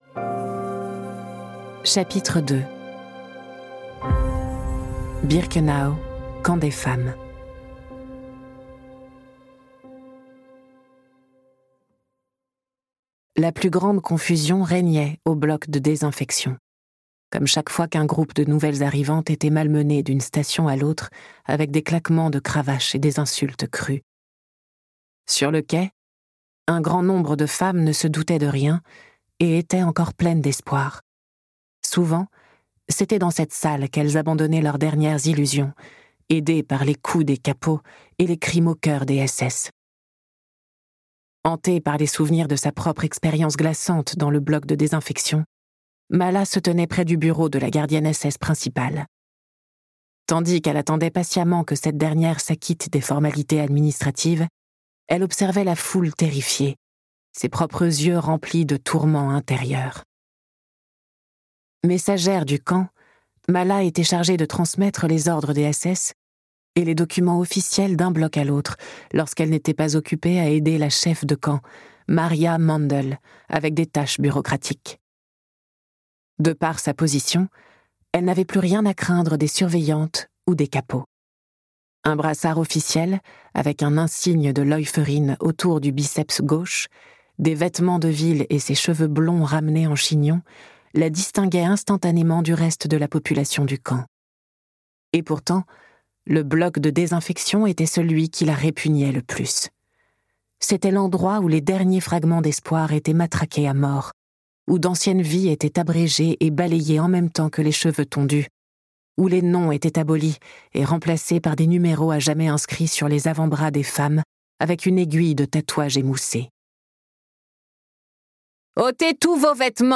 Ce livre audio est interprété par une voix humaine, dans le respect des engagements d'Hardigan.